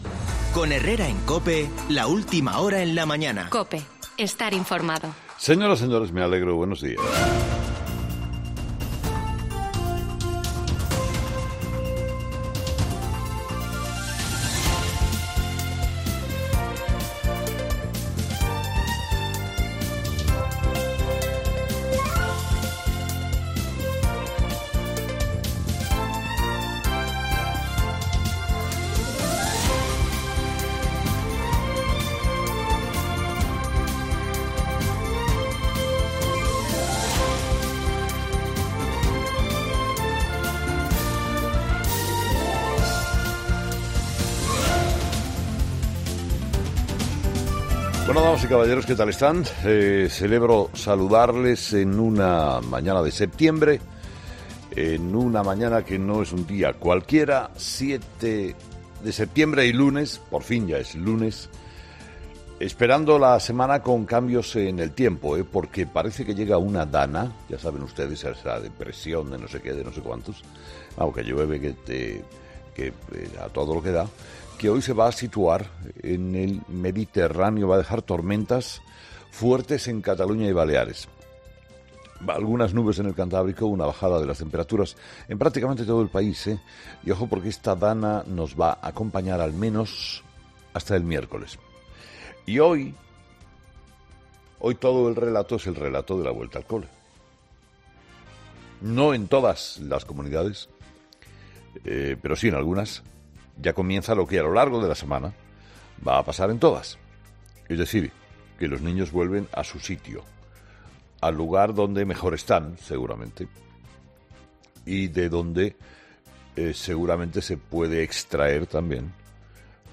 El periodista de la Cadena COPE, Carlos Herrera, ha arrancado su primer monólogo de la semana con dos de los temas que van a marcar la jornada del lunes, día 7 de septiembre, y la semana.